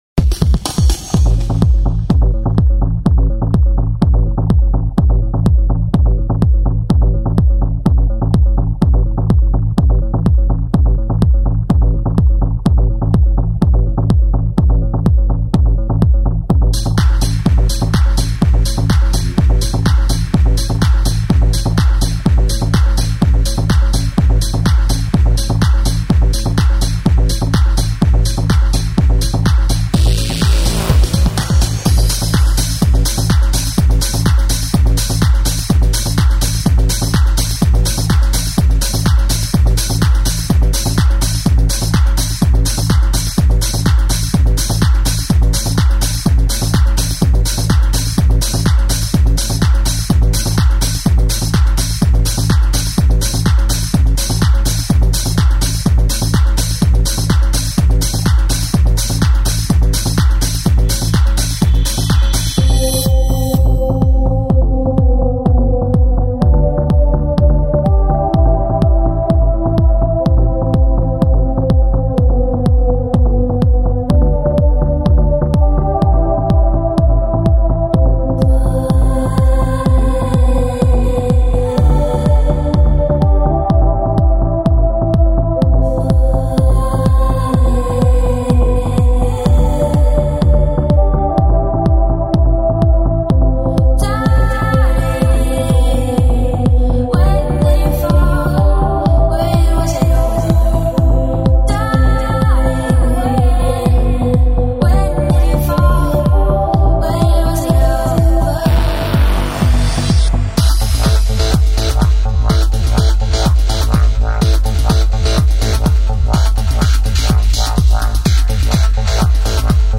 /Various Artists/house/trance/